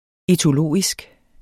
Udtale [ etoˈloˀisg ]